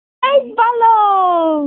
New Balloon Botón de Sonido